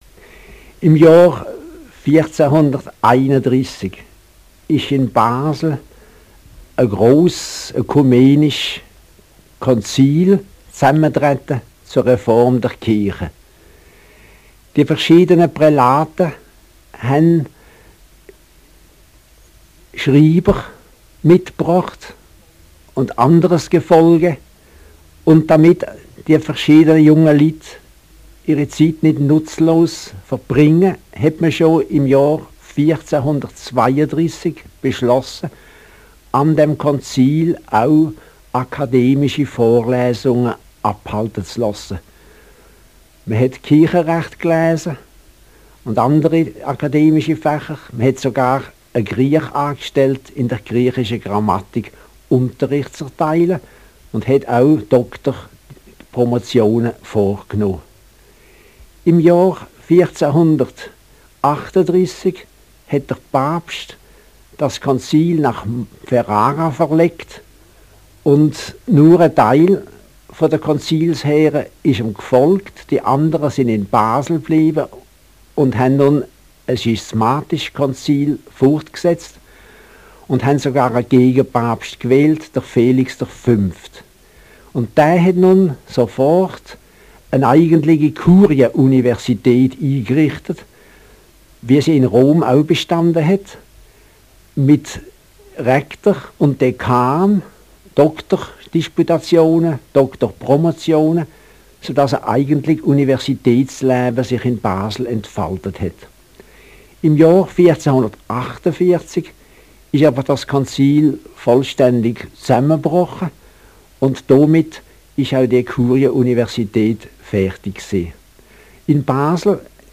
zum Jubiläum von 1960